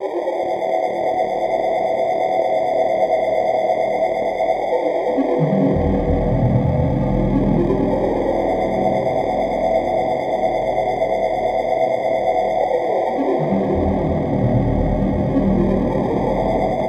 Index of /90_sSampleCDs/Club_Techno/Atmos
Atmos_03_C3.wav